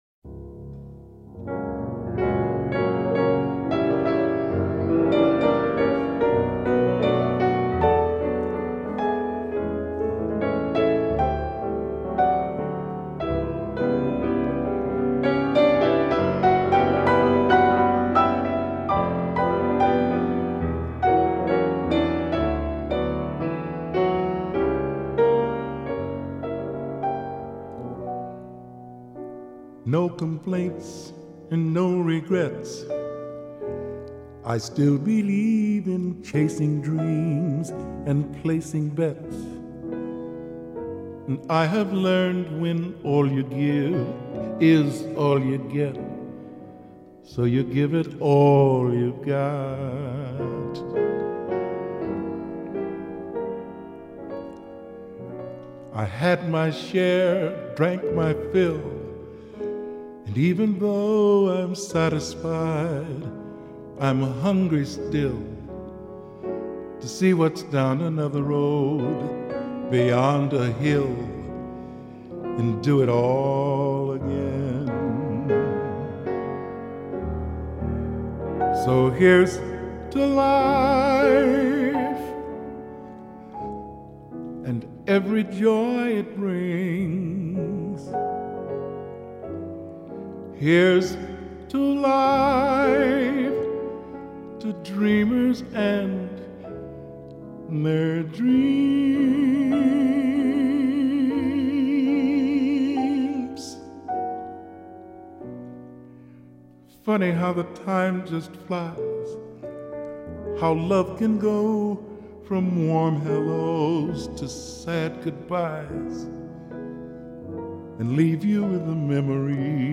LEGENDARY JAZZ PIANIST AND COMPOSER
それは歌もピアノも絶品以外の何ものでもない。